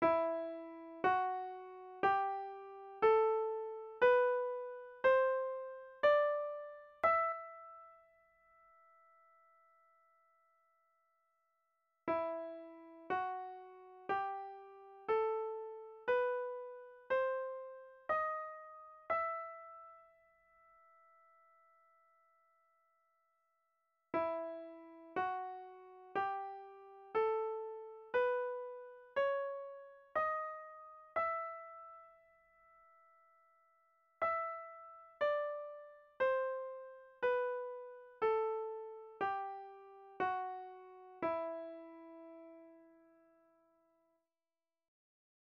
V první ukázce zazní mollové stupnice v pořadí
aiolská - harmonická - melodická
mollové stupnice.mp3